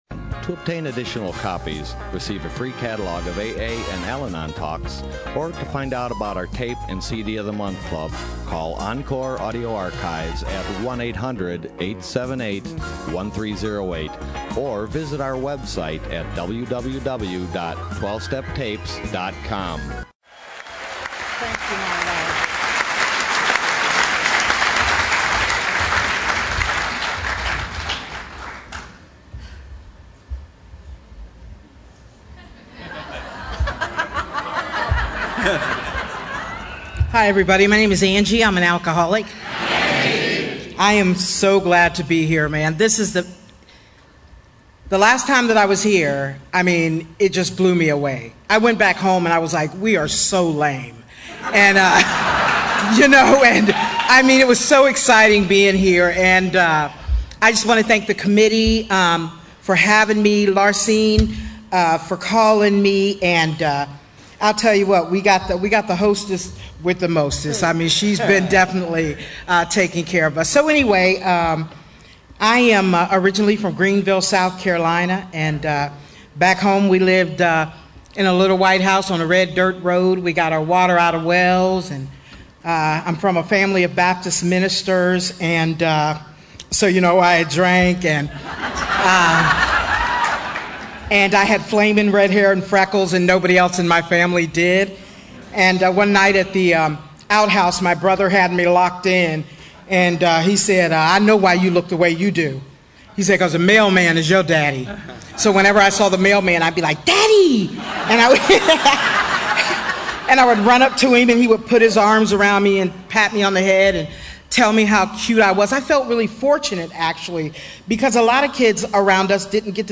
SOUTHBAY ROUNDUP 2015